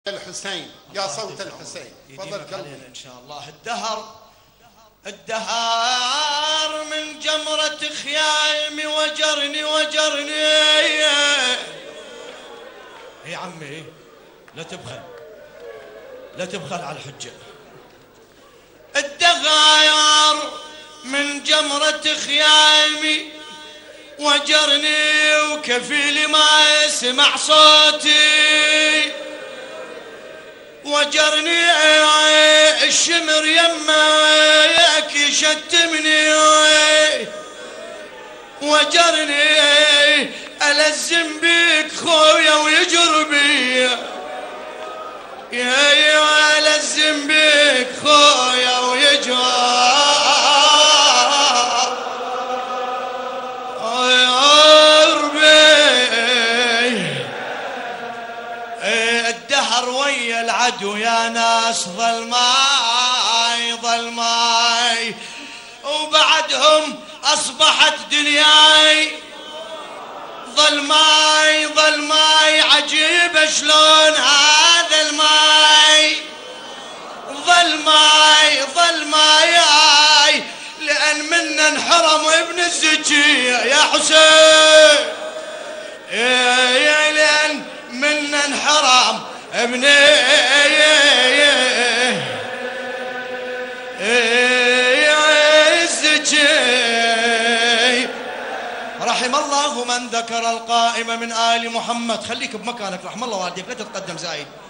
ذكرى الأربعين 1434 هـ